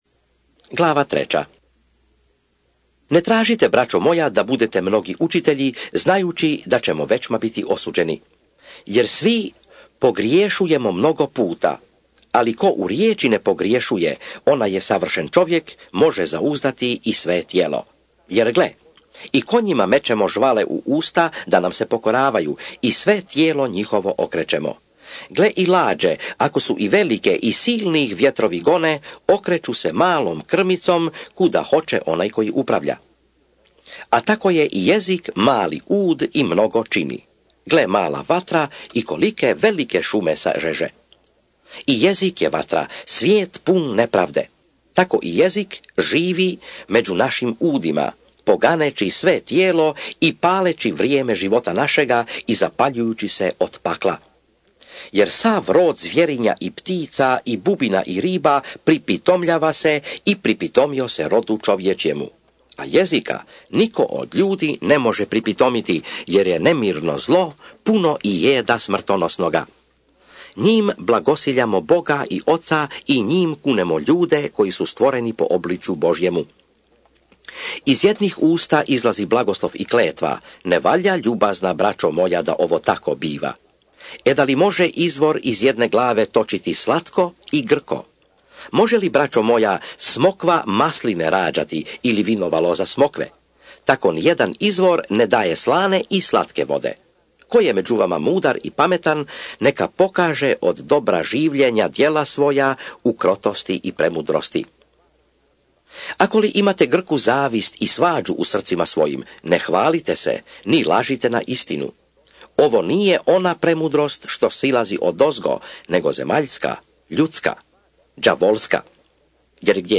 JAKOV(ČITANJE) - Bible expounded
SVETO PISMO – ČITANJE – Audio mp3 JAKOV glava 1 glava 2 glava 3 glava 4 glava 5